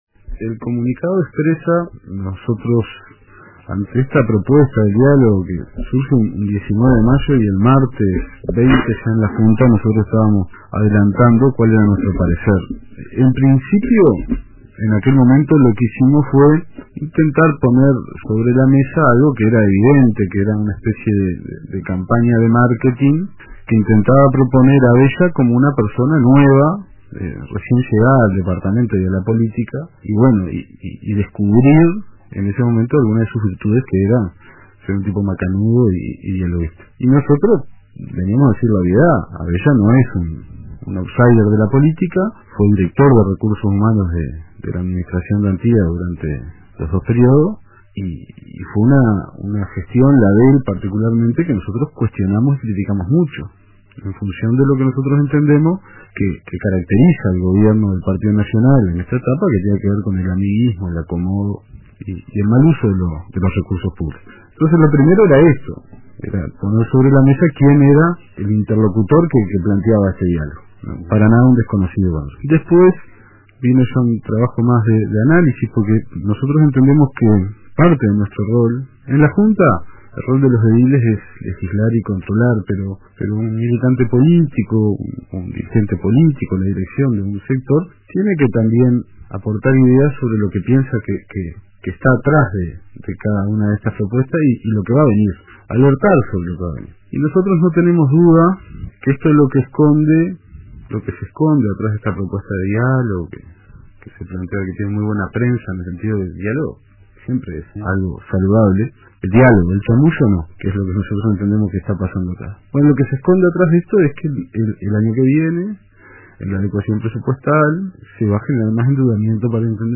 El Secretario General del Partido Comunista en Maldonado, el edil Nicolás Billar, participó del programa Radio Con Todos de RBC y explicó los motivos de la decisión.